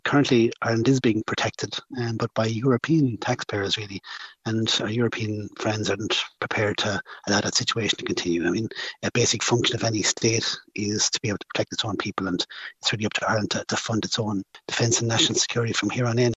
Cathal Berry, former Army Ranger, says Ireland needs to fund its own defense and national security: